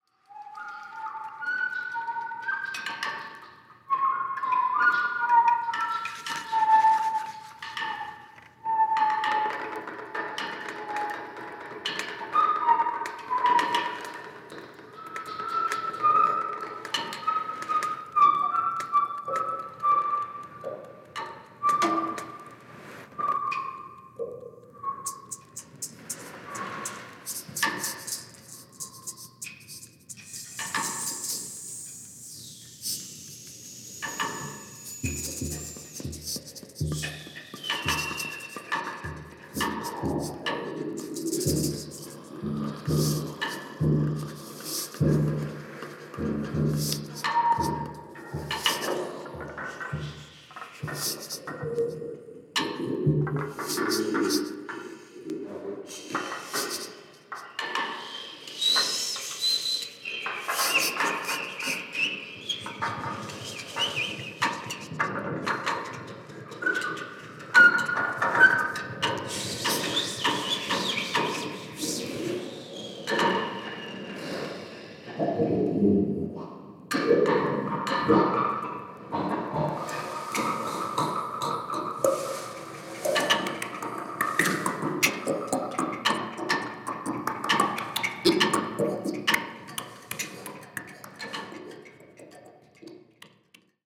Piano, Bells, Gong, Percussion
Saxophone, Clarinet, Flute